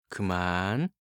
알림음 8_그만5-남자.mp3